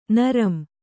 ♪ naram